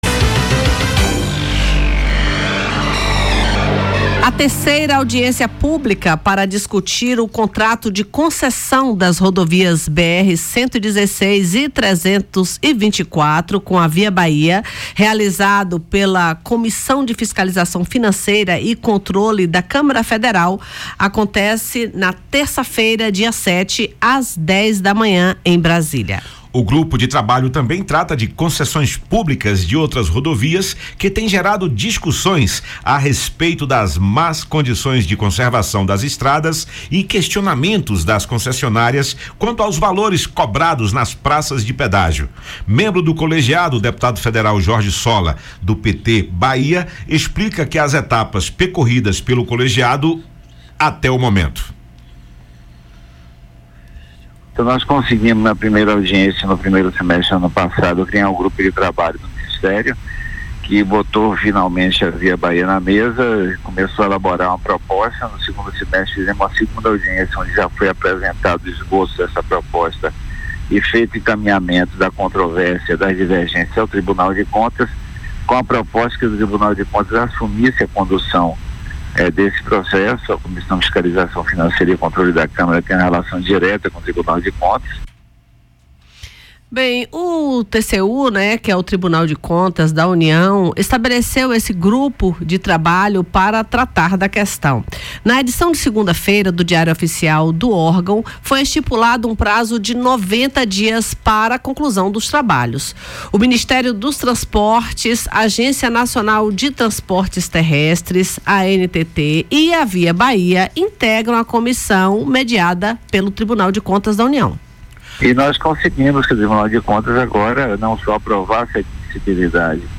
Membro do colegiado, o deputado federal Jorge Solla (PT-BA) explica quais as etapas percorridas pelo colegiado, até o momento.